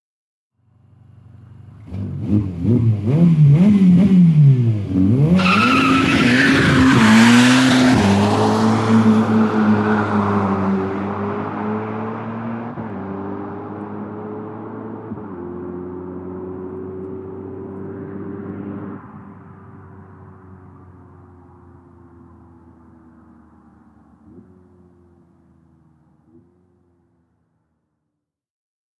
Ferrari-F8-spider.mp3